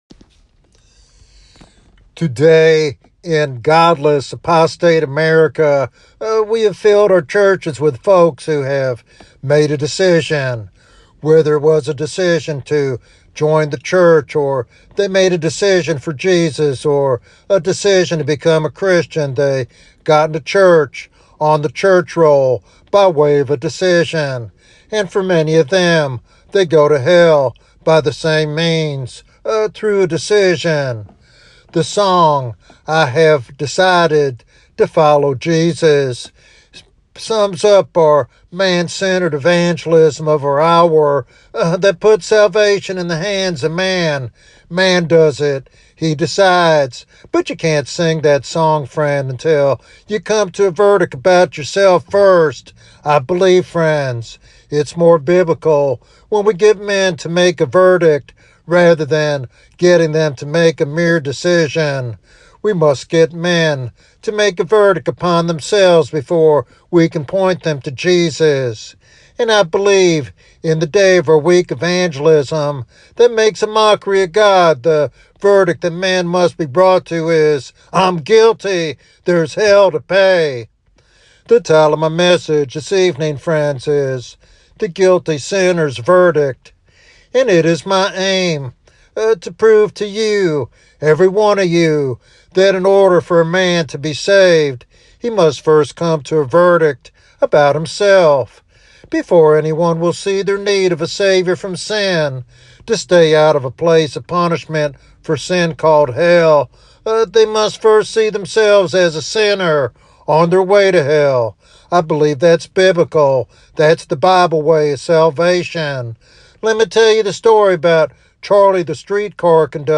This evangelistic sermon underscores that true salvation begins only when one acknowledges their lost condition and turns to Jesus as the only remedy.